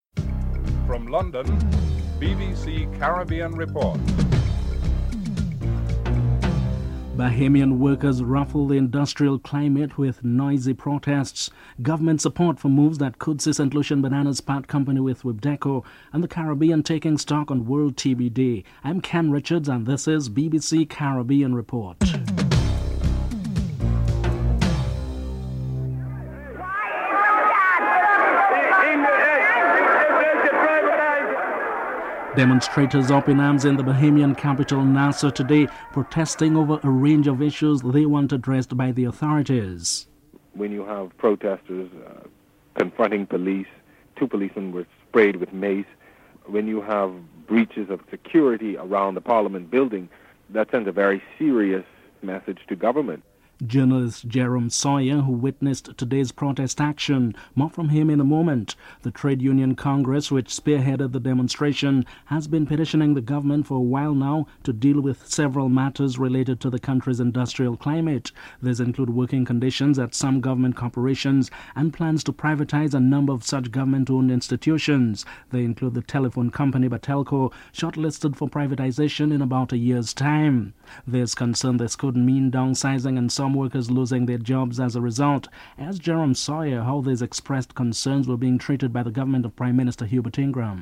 Chief Minister David Brandt comments on the cost of construction and inadequate facilities.
Agriculture Minister Cassius Elias discusses the initiatives.